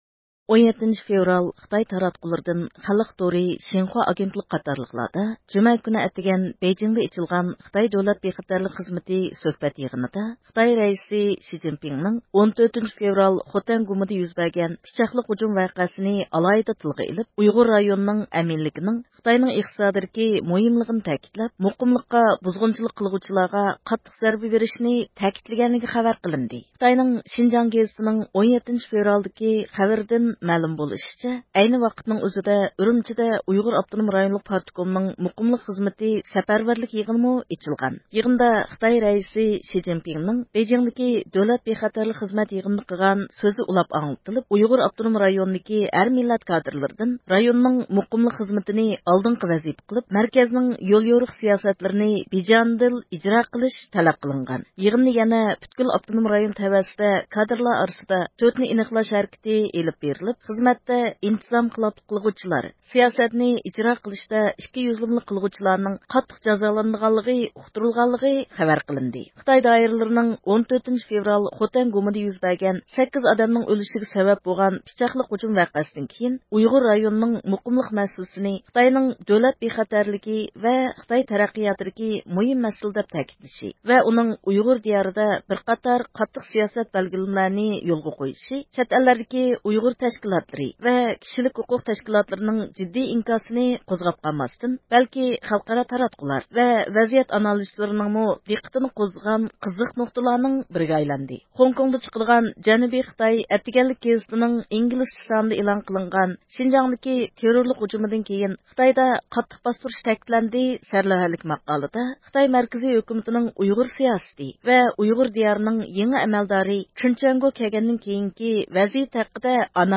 بۈگۈن رادىيومىز زىيارىتىنى قوبۇل قىلىپ